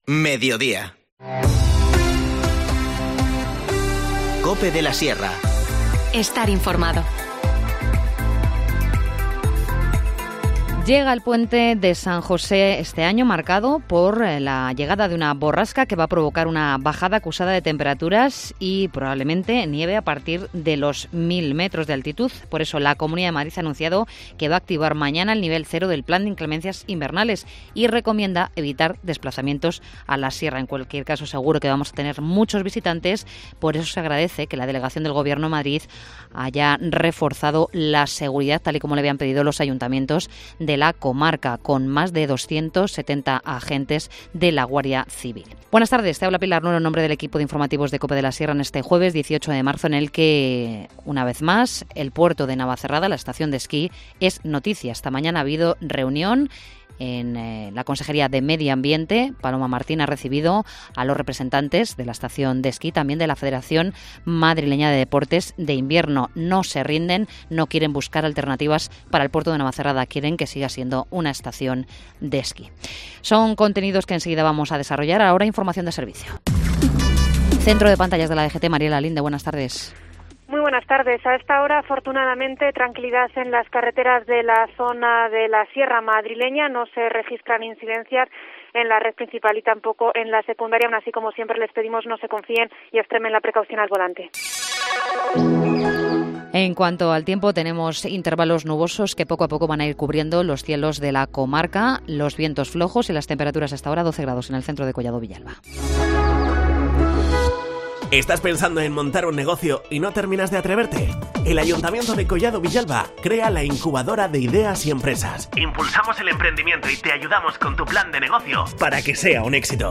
Informativo Mediodía 18 marzo